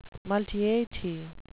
multeity (MUL-tee-i-tee) noun
Pronunciation: